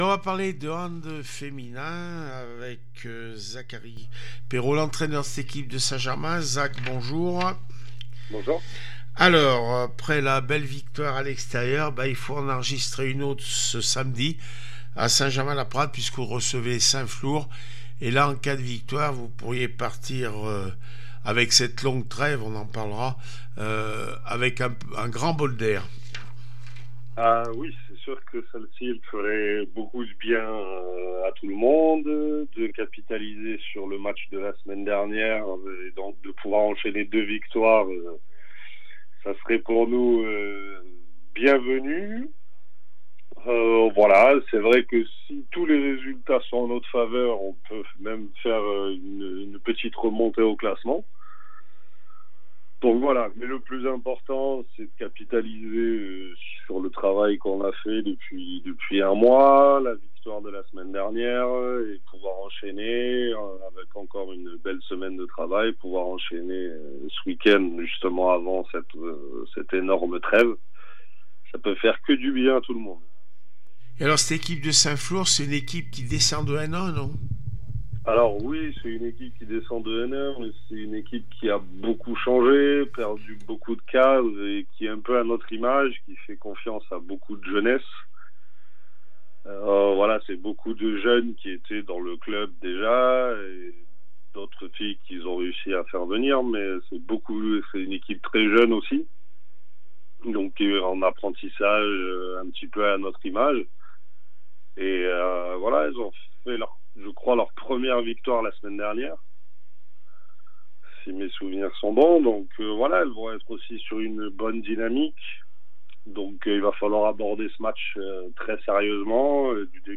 22 novembre 2024   1 - Sport, 1 - Vos interviews